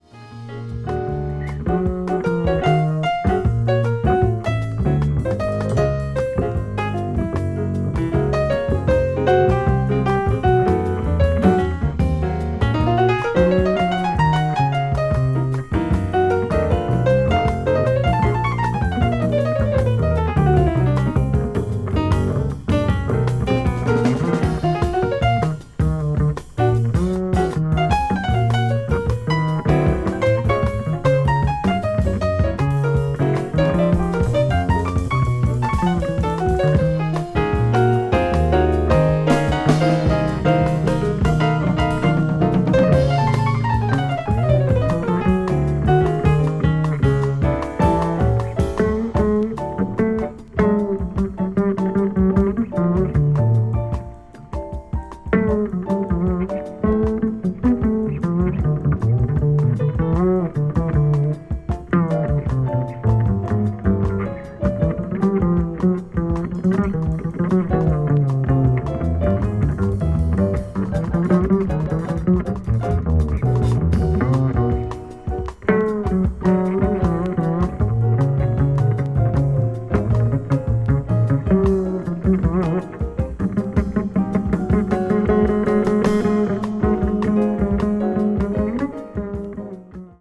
contemporary jazz   modal jazz   post bop   spritual jazz